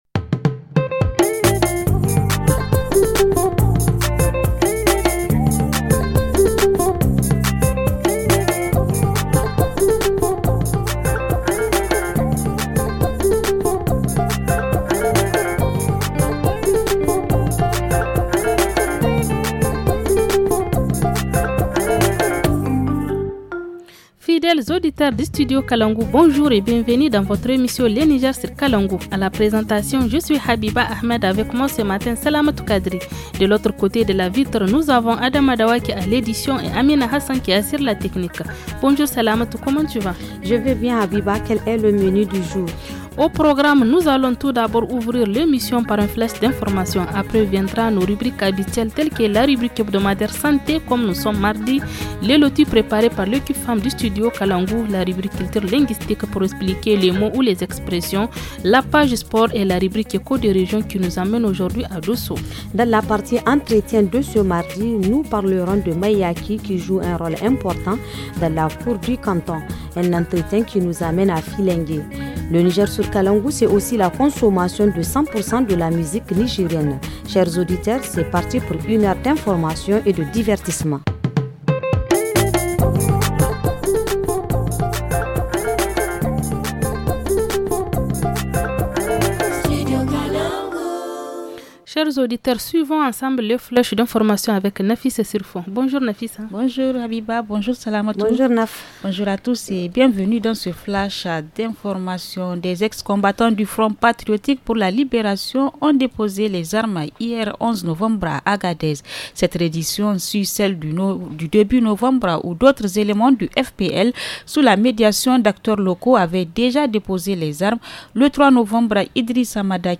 Dans l’émission de ce mardi : Le rôle du « Mayaki » dans la chefferie traditionnelle du canton de Tonkandia (département de Filingué). Dans la rubrique hebdomadaire, focus sur les signes annonciateurs d’un accident vasculaire cérébral (AVC). En reportage région, direction Dosso où certaines façons de porter le hidjab sont jugées inappropriées.